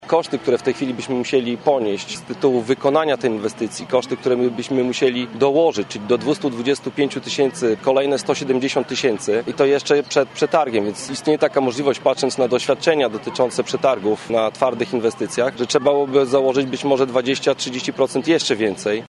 Karol Sobczak, burmistrz Olecka zauważa, że stawki mogą pójść w górę jeszcze bardziej po ogłoszeniu przetargu.